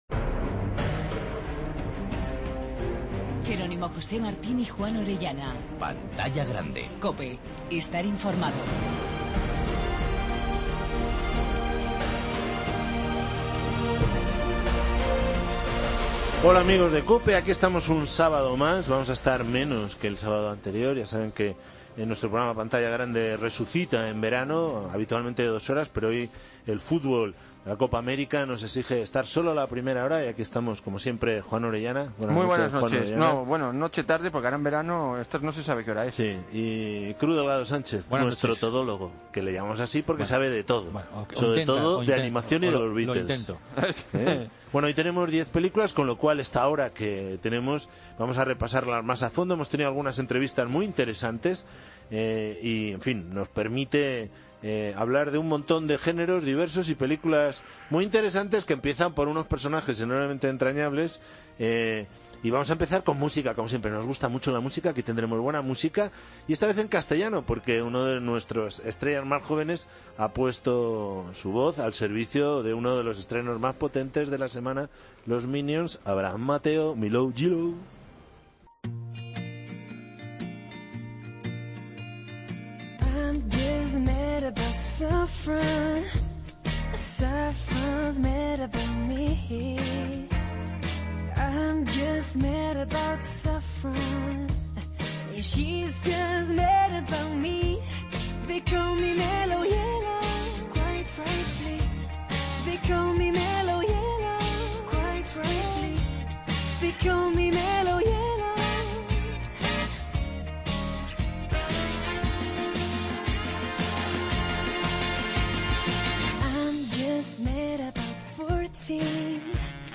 AUDIO: De 21:00 a 21:30 h.: Críticas de los estrenos de cine del 3 de julio.